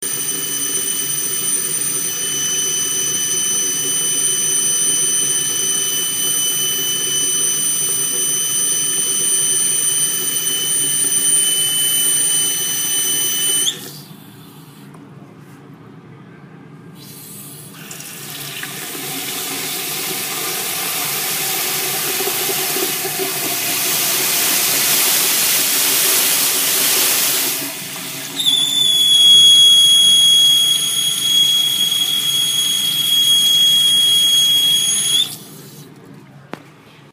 Why is his bathroom sink making this noise?